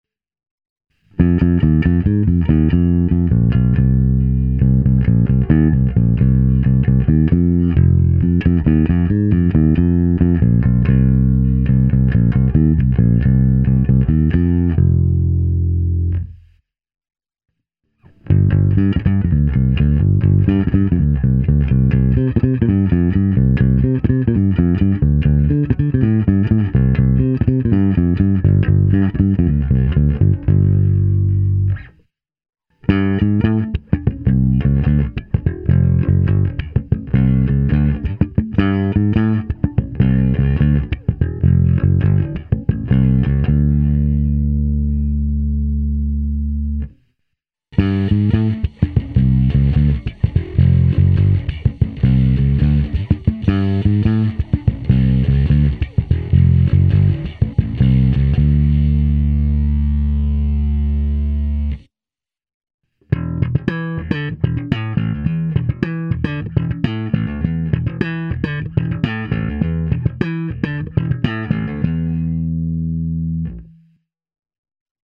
Bručivá, agresívnější, skvěle použitelná i na slapovou techniku.
Bonusová ukázka přes AmpliTube se zapnutou simulací basového aparátu snímaného mikrofony a doplněno zkreslením.